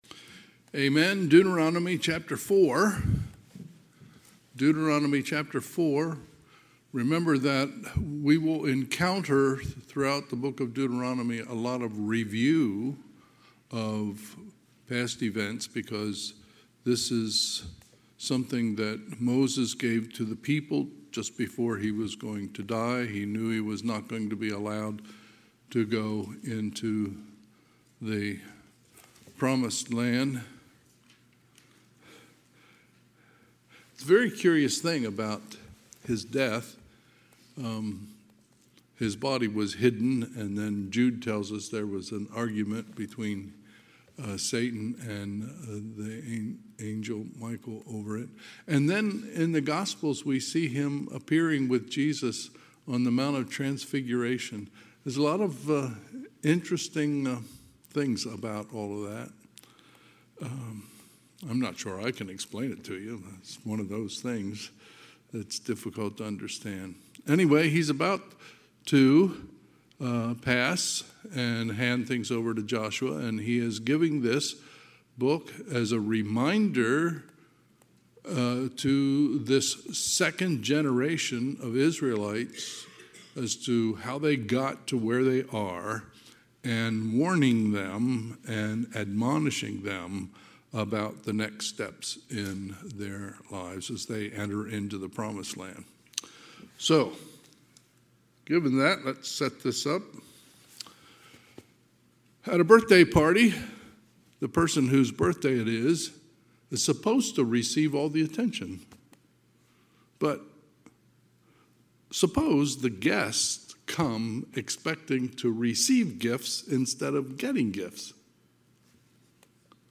Sunday, November 16, 2025 – Sunday PM